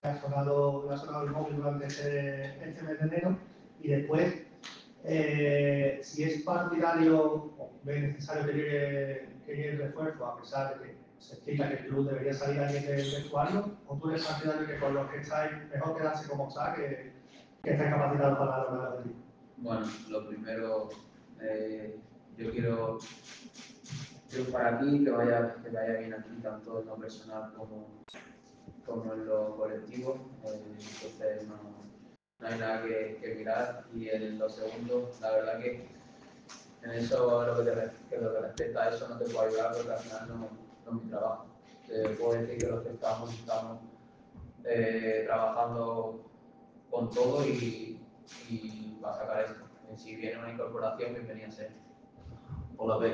Gerard Fernández «Peque» volvió a situarse en el foco mediático tras comparecer en la sala de prensa del Estadio Jesús Navas.